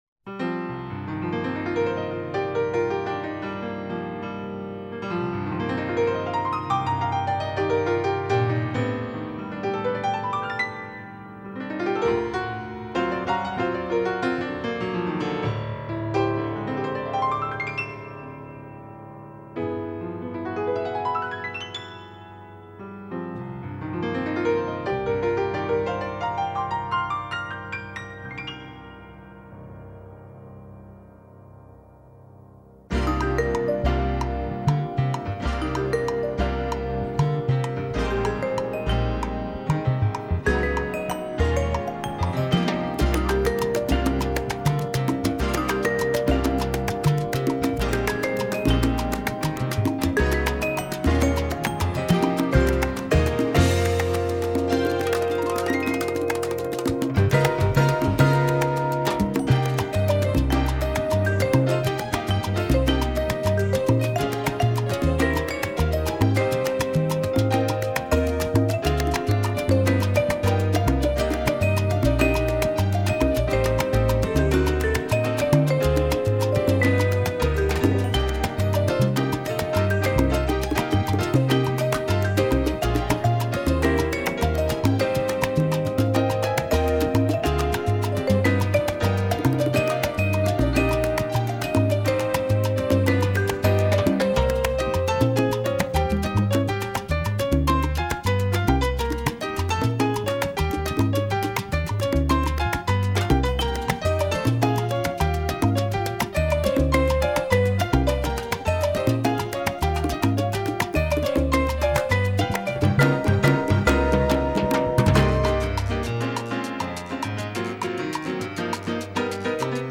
Category: combo
Style: mambo
Solos: open
Instrumentation: combo (quintet) vibes, rhythm (4)